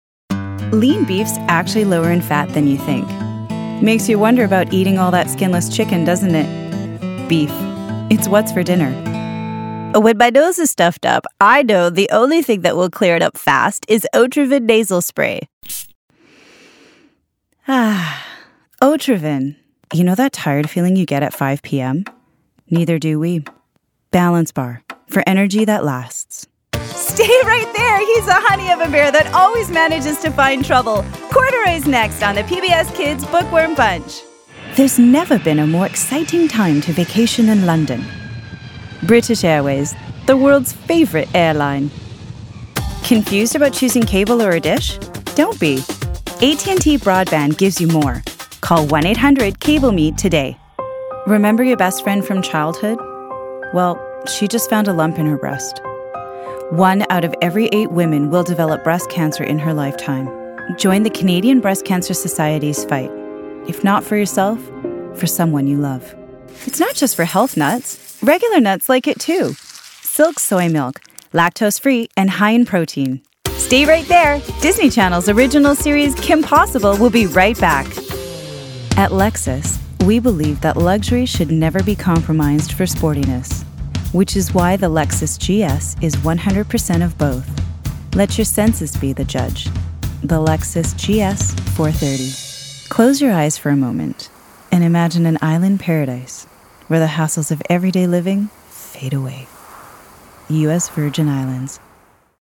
voice over demo